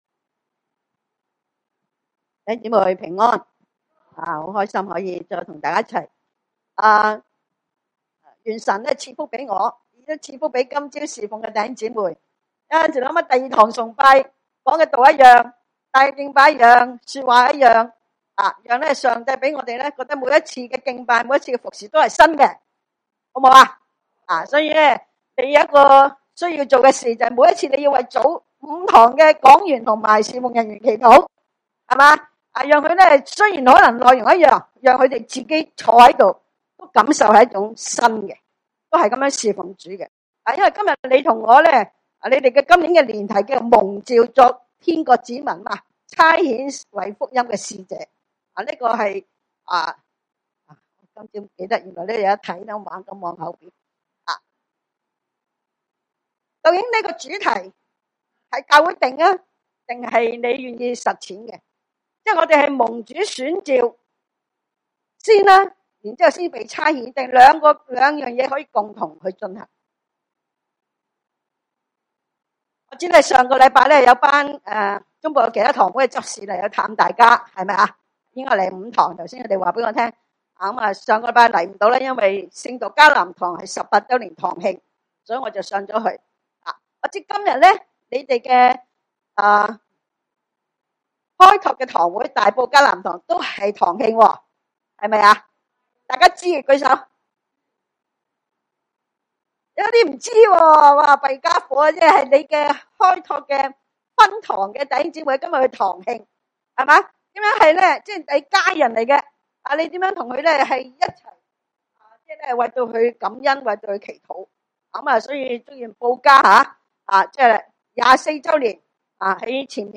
腓1：1-11 崇拜類別: 主日午堂崇拜 1 基督耶穌的僕人保羅和提摩太寫信給凡住腓立比、在基督耶穌裏的眾聖徒，和諸位監督，諸位執事。